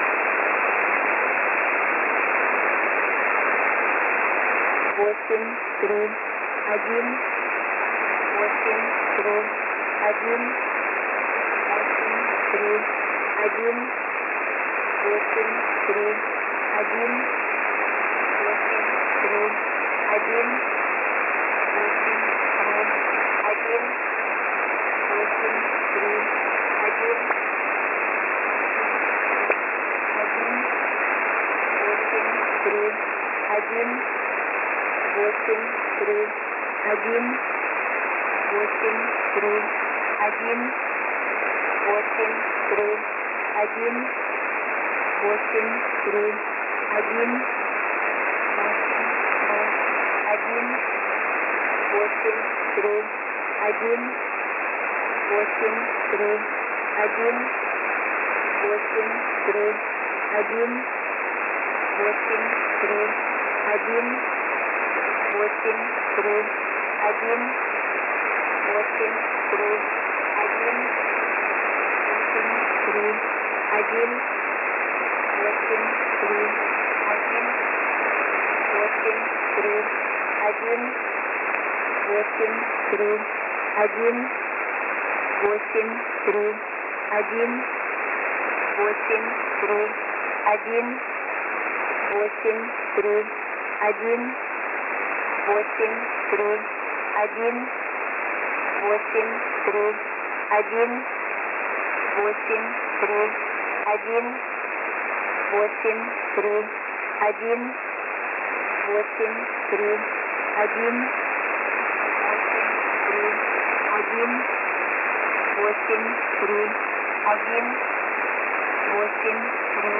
Mode: USB + Carrier Frequency: 8420